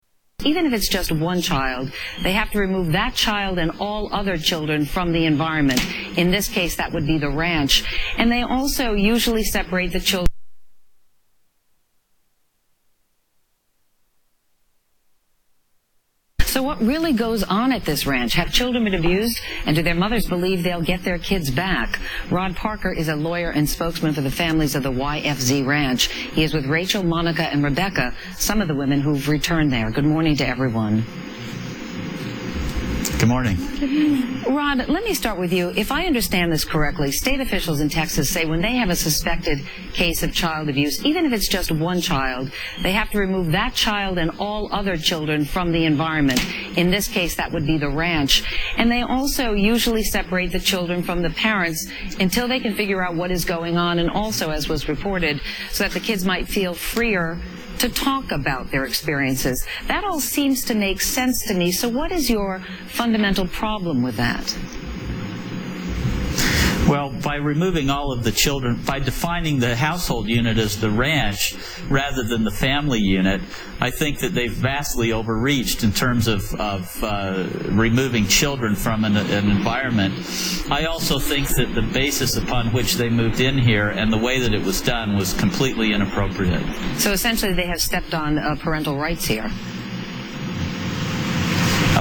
Polygamist women interview 1